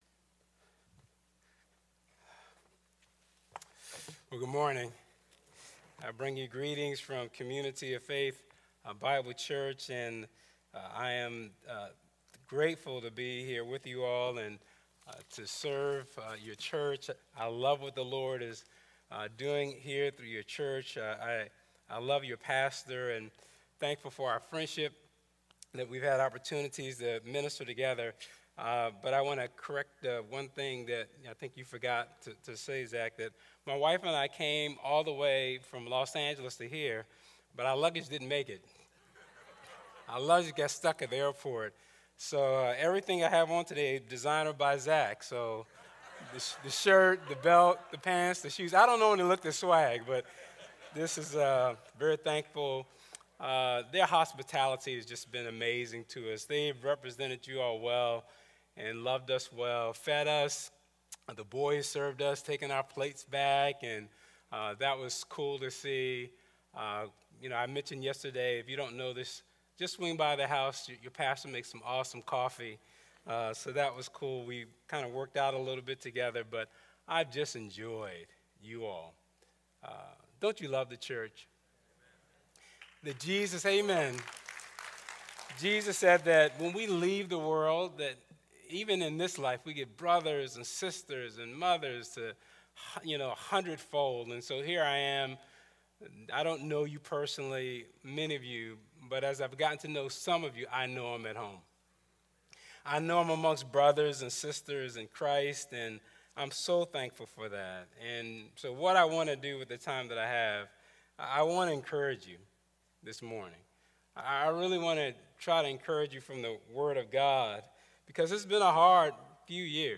A message from the series "Behold our God!."